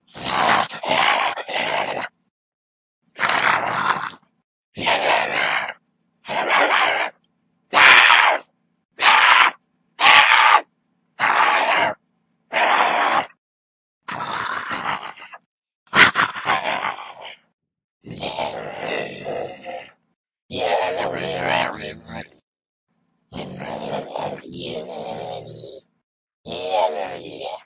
Tag: 邪恶 外星人 令人毛骨悚然 恶魔 恐怖 吓人